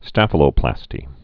(stăfə-lō-plăstē)